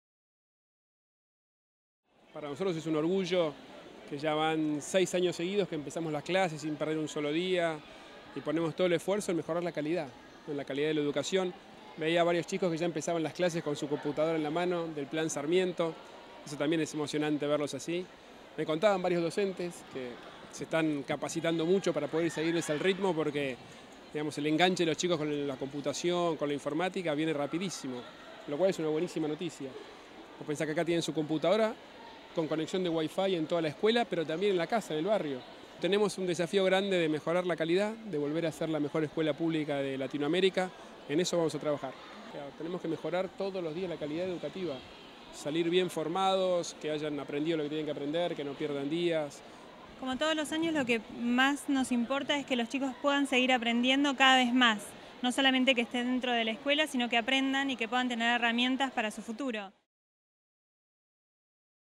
El jefe de Gobierno de la Ciudad de Buenos Aires, Horacio Rodríguez Larreta, encabezó un acto en una escuela del barrio de La Boca para dar inicio oficial al ciclo lectivo 2016.